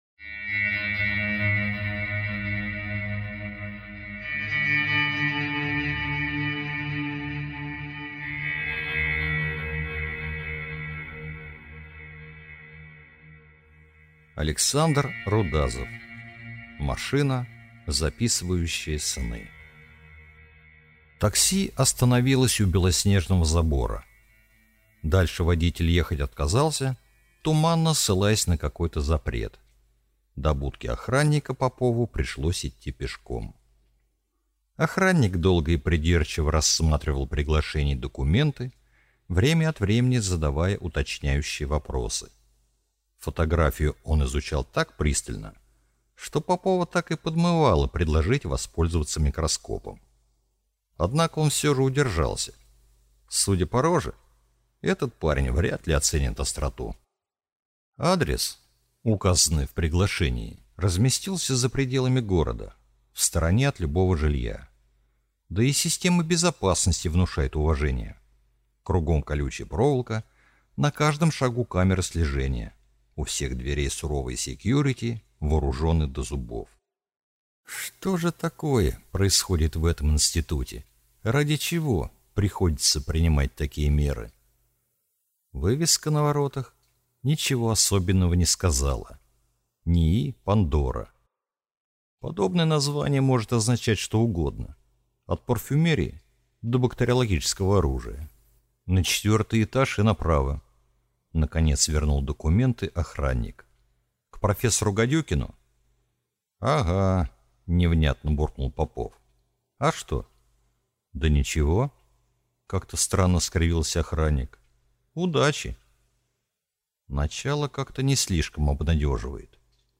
Аудиокнига Машина, записывающая сны | Библиотека аудиокниг